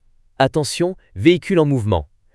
Voice Alarm FR official.wav